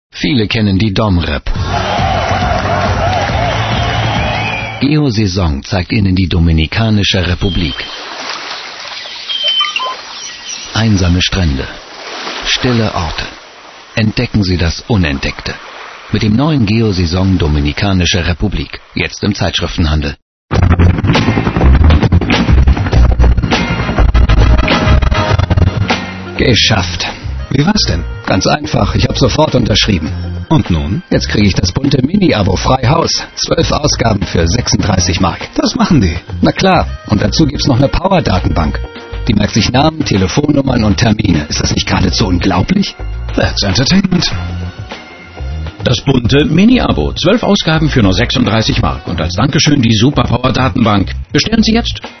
deutscher Synchronsprecher.
Kein Dialekt
Sprechprobe: Sonstiges (Muttersprache):